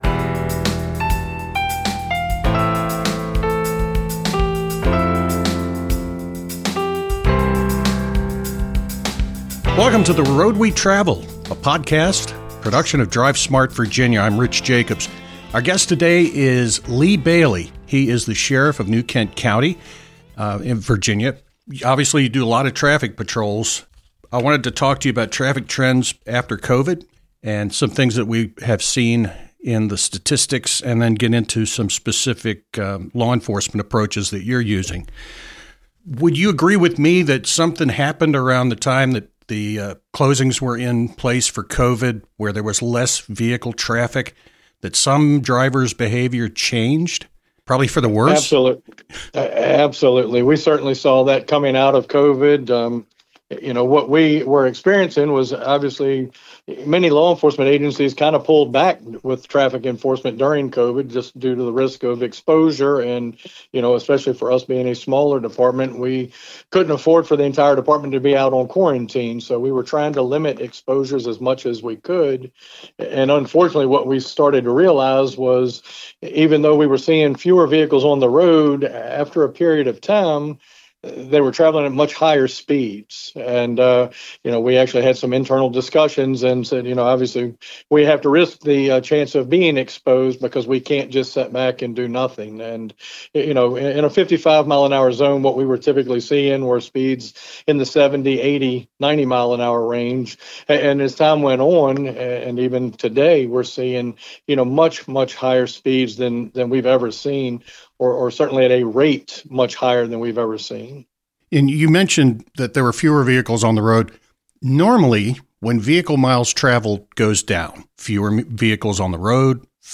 The Road We Travel, presented by DRIVE SMART Virginia, is an interview-format podcast focused on driver behavior, traffic laws, and emerging transportation technology.
New Kent County Sheriff Lee Bailey is our guest. We take a look at pre- and post-COVID traffic trends, then talk about New Kent's automated speed limit enforcement campaign.